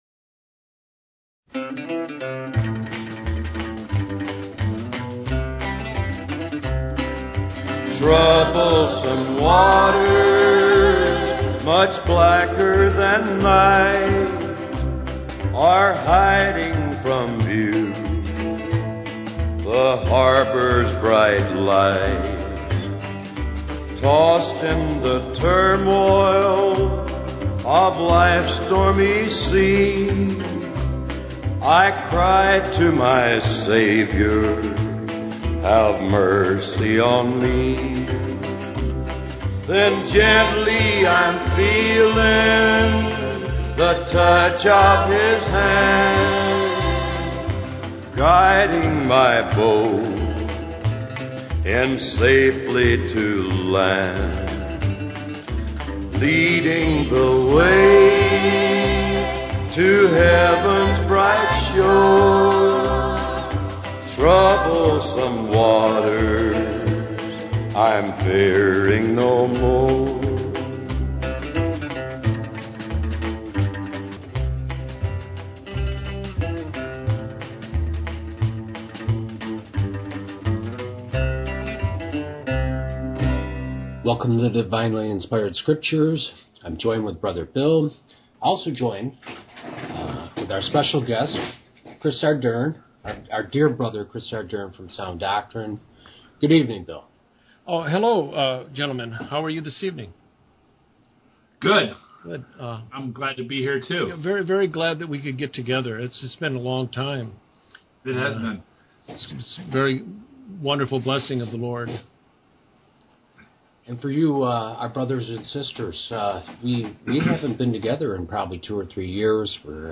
Watchmen Round Table